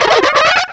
Cri de Mustébouée dans Pokémon Diamant et Perle.